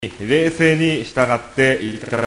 そのような、タイミングの問題で興味深いのが、2011年3月12日午前2時頃に官邸で行われた、 枝野元官房長官による地震及び原発の状況に関する記者会見である。